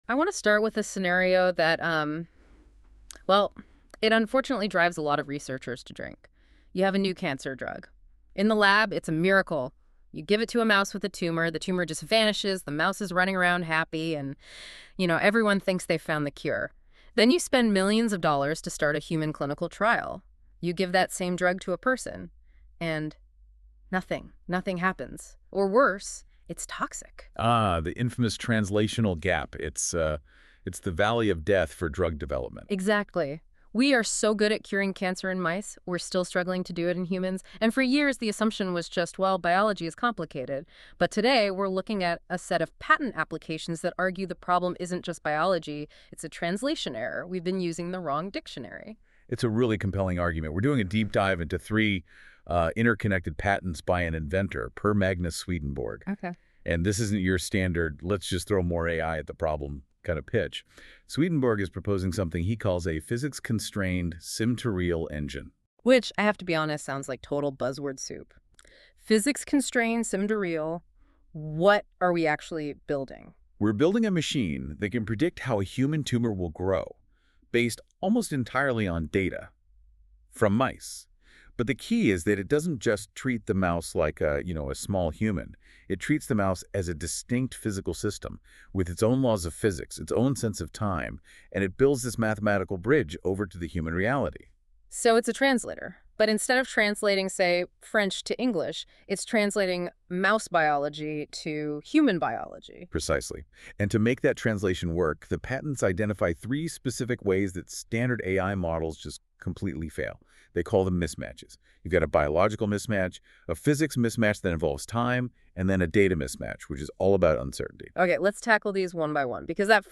AI-generated podcast covering patent innovations, cross-species transfer learning, and clinical implications.